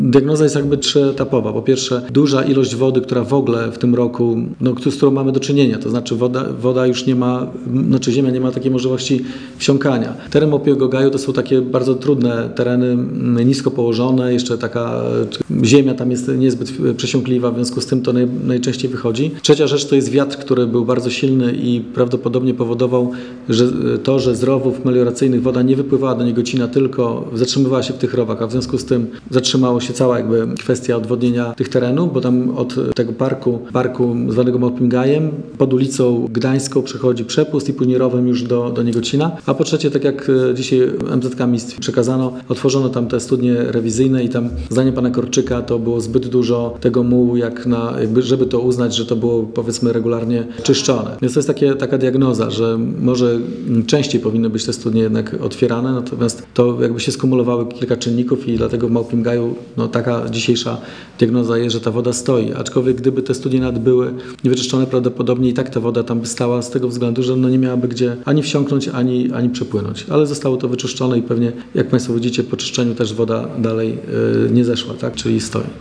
– Opady deszczu, silne wiatry i najprawdopodobniej niedrożna kanalizacja deszczowa to powód wylania stawu. Miejski Zakład Komunalny od rana zajął się tą sprawą – zapewniał w poniedziałek (30.10.) na konferencji prasowej Wojciech Karol Iwaszkiewicz, burmistrz Giżycka.